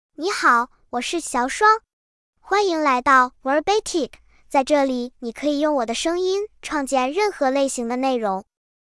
XiaoshuangFemale Chinese AI voice
Xiaoshuang is a female AI voice for Chinese (Mandarin, Simplified).
Voice sample
Listen to Xiaoshuang's female Chinese voice.
Xiaoshuang delivers clear pronunciation with authentic Mandarin, Simplified Chinese intonation, making your content sound professionally produced.